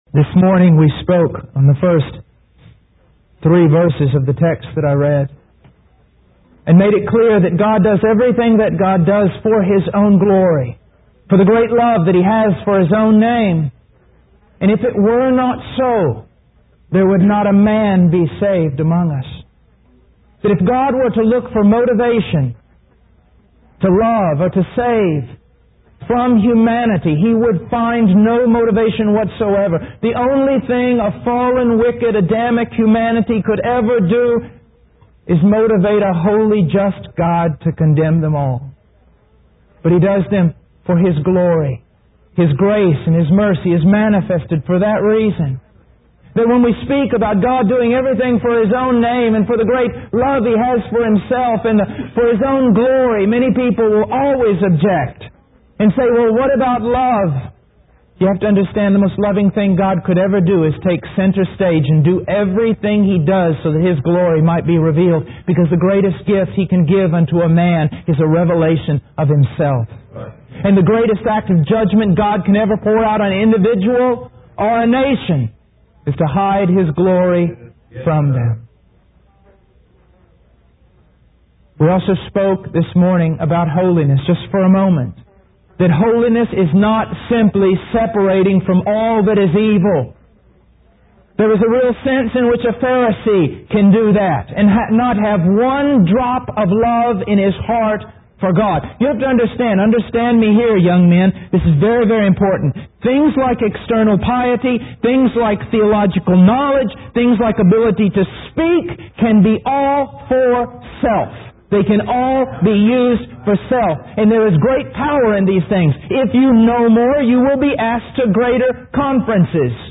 In this sermon, the preacher emphasizes the challenges and distractions that believers face when serving Jesus. He contrasts the ease of serving Jesus in comfortable environments with the difficulties that arise when faced with worldly temptations. The preacher highlights the importance of relying on the power of the Holy Spirit and the message of salvation through biblical preaching to transform hearts.